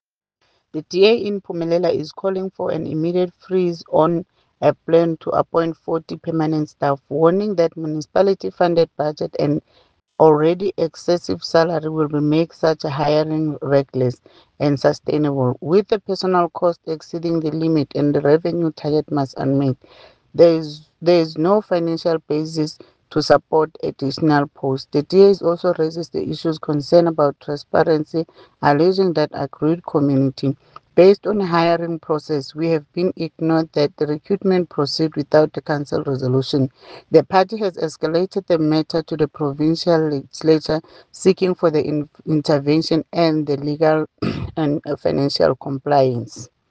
English soundbite by Cllr Ntombi Mokoena,